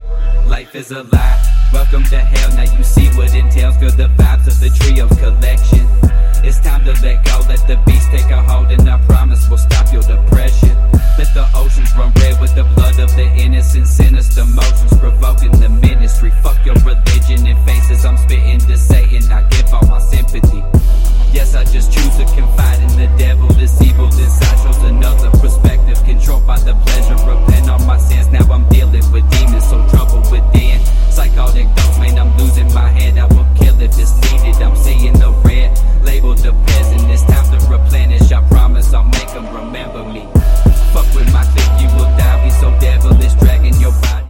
• Качество: 192, Stereo
басы
качающие
Rap
мрачные
phonk
Мрачный рэп